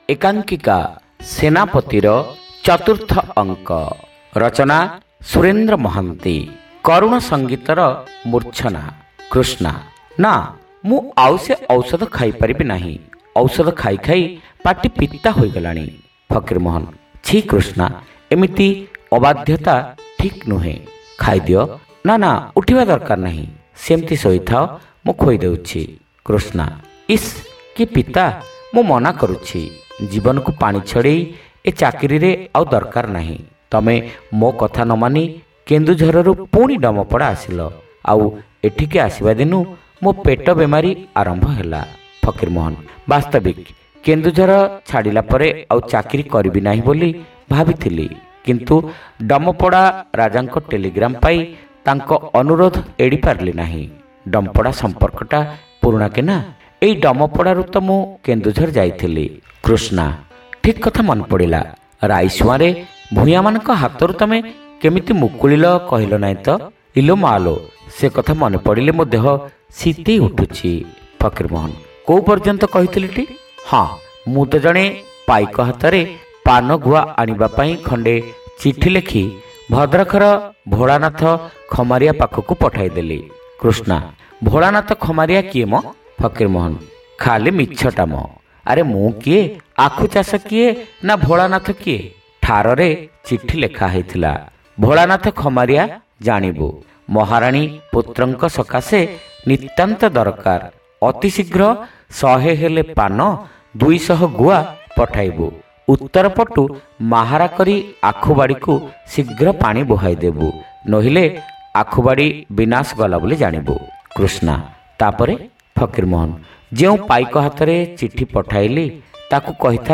ଶ୍ରାବ୍ୟ ଏକାଙ୍କିକା : ସେନାପତି (ସପ୍ତମ ଭାଗ)